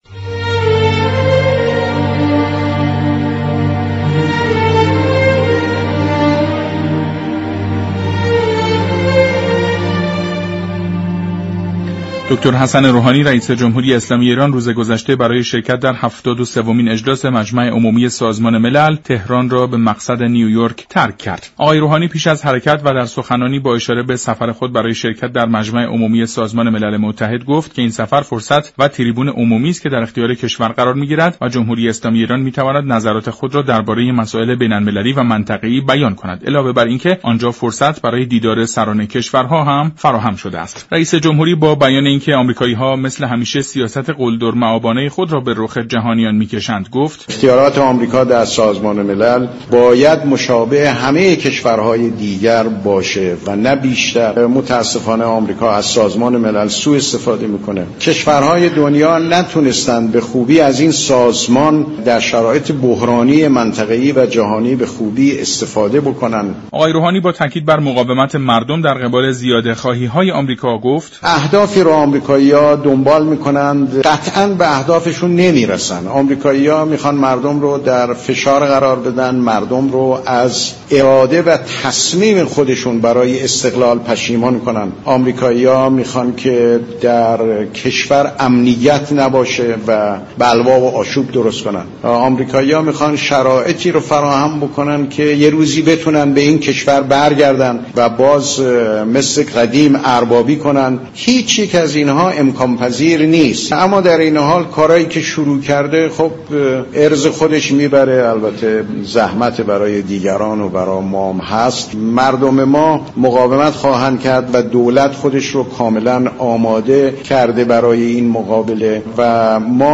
رامین مهمانپرست سخنگوی پیشین وزارت امور خارجه در گفت و گو با رادیو ایران گفت